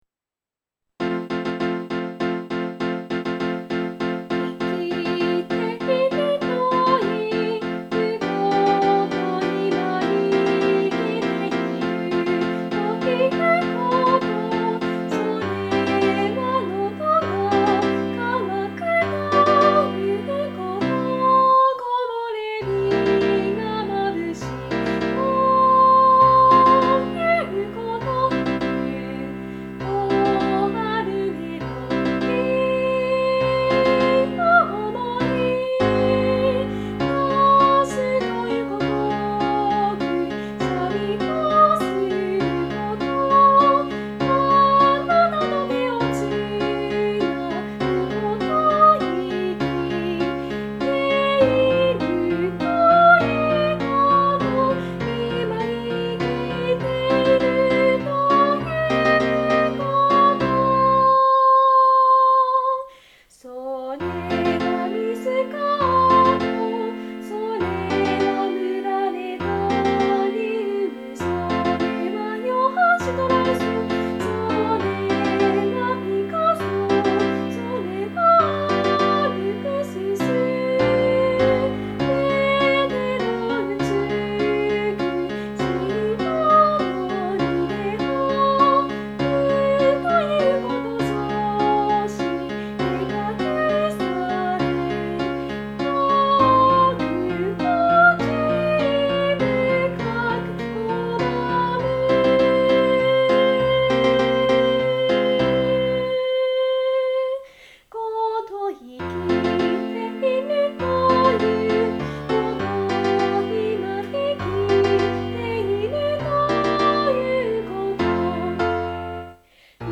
作詞 ： 谷川俊太郎 『生きる』 より
作曲 ： Paolo Giuseppe Gioacchino Concone
live.mp3